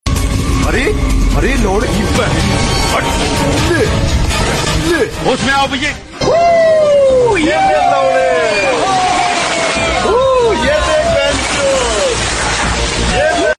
Listen to Whoooooo yeleeee laudeeee, a memes sound button featuring laudeeee, memesounds, socialmedia on InstantSFX.